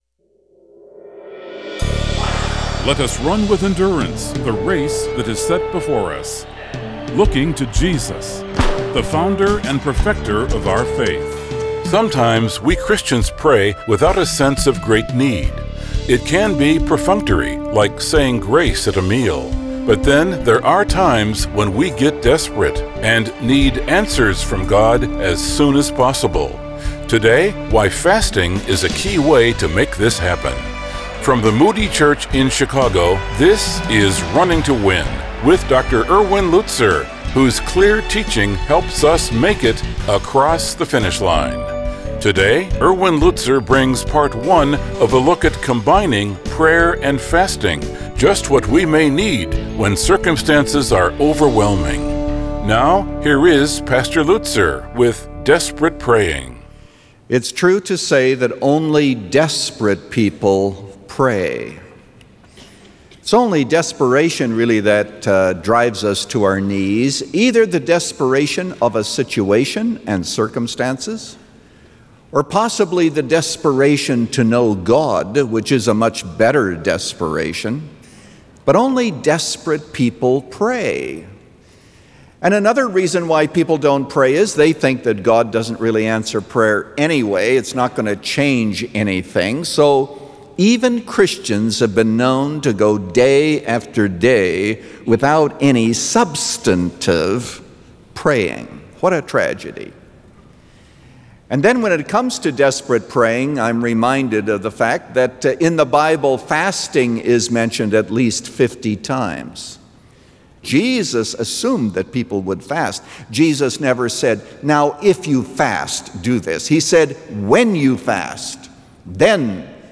Desperate Praying – Part 1 of 2 | Radio Programs | Running to Win - 15 Minutes | Moody Church Media